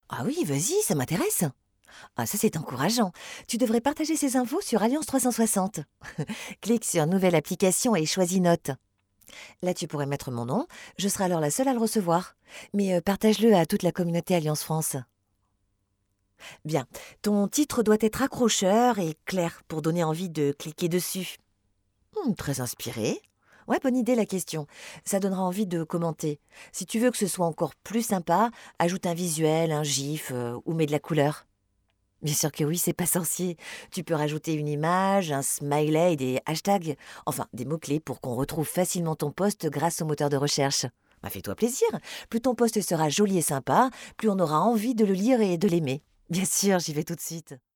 20 years of experience of voice over, on radio Tv and other types of recordings
Sprechprobe: Sonstiges (Muttersprache):